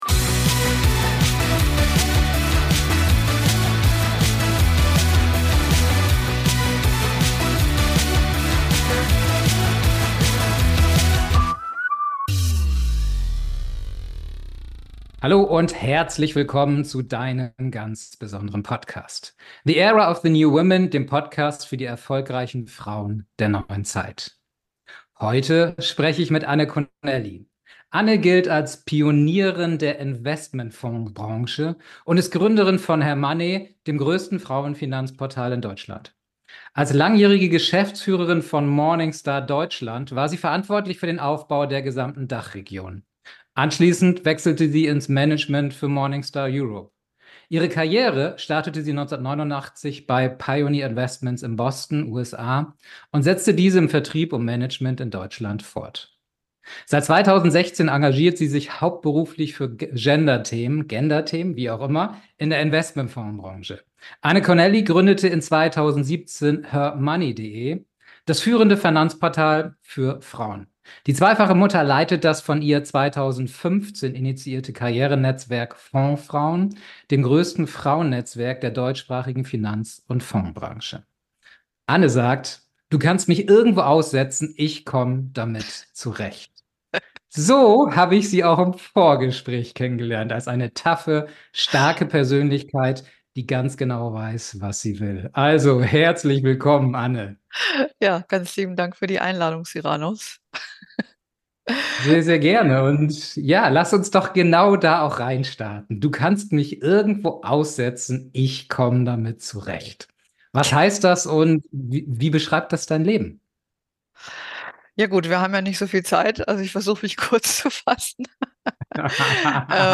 #017 Kein Bock auf Altersarmut bei Frauen. Interview